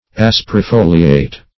Search Result for " asperifoliate" : The Collaborative International Dictionary of English v.0.48: Asperifoliate \As`per*i*fo"li*ate\, Asperifolious \As`per*i*fo"li*ous\, a. [L. asper rough + folium leaf.]